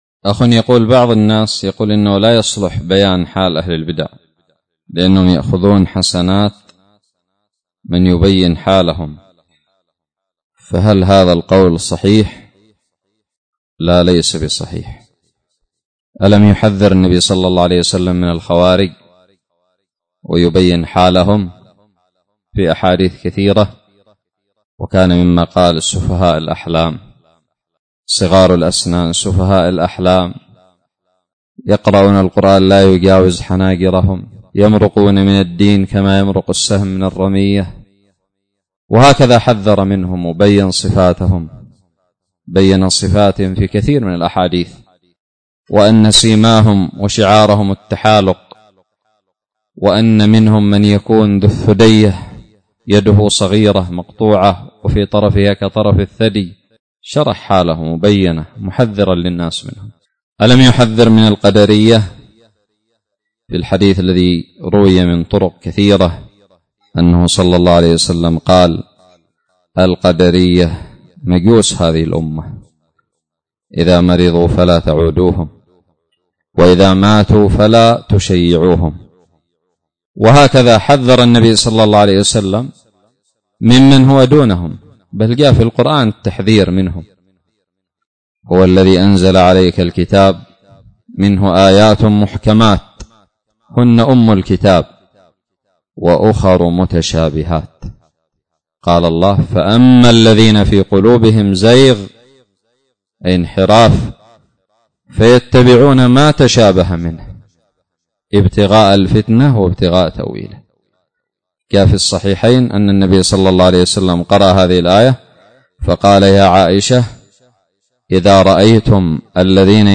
فتاوى منهجية